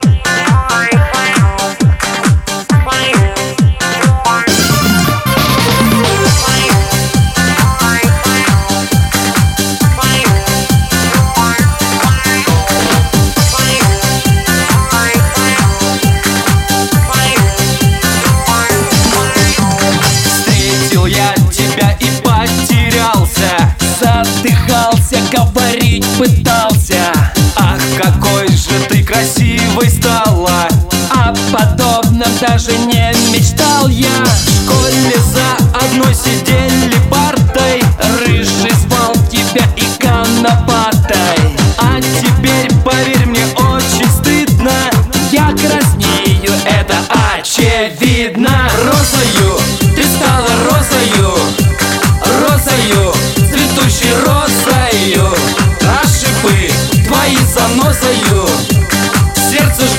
Pop
Лучшие танцевальные треки наступающего сезона!